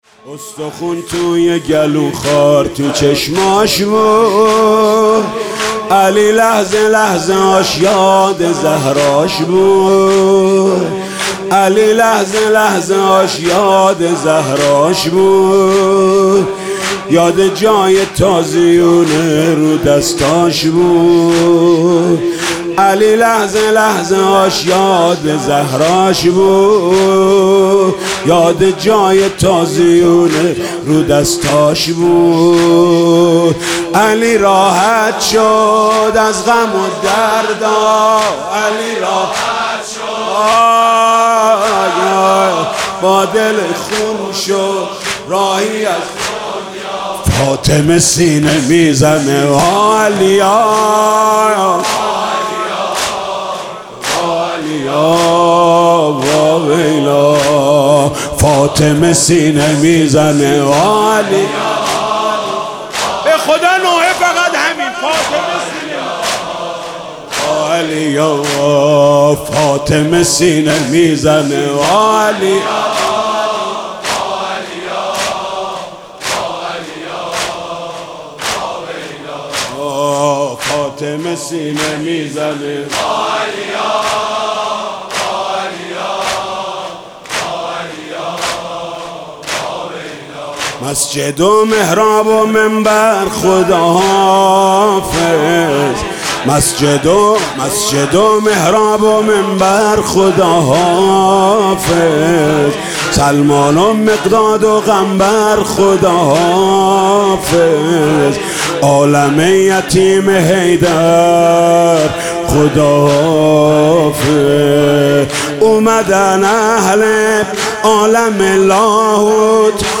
گزارش صوتی شب بیست دوم ماه رمضان در هیئت رایةالعباس(ع)
بخش اول - روضه ( دلبر دلش گرفته دلدار گریه کرده )